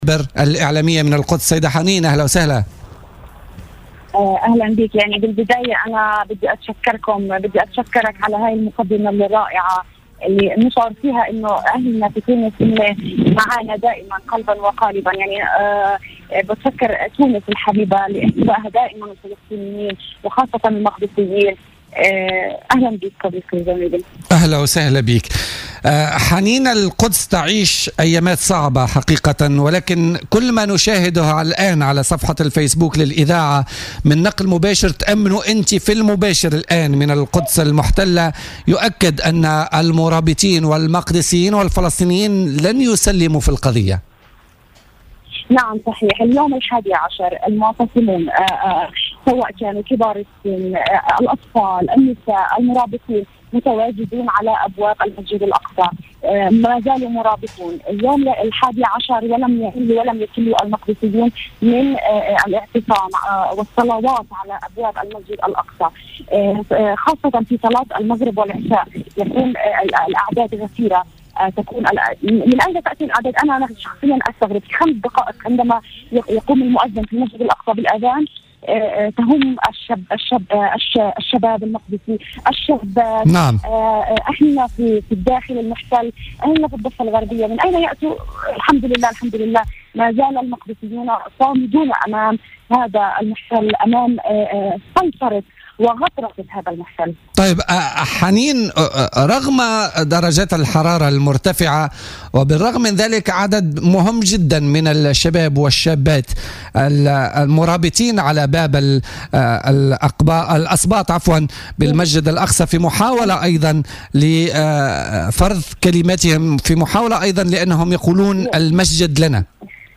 وأضافت لـ "الجوهرة اف أم" في اتّصال هاتفي ببوليتيكا أن المرابطين هم من جميع الشرائح العمرية من نساء ورجال وبينهم أطفال.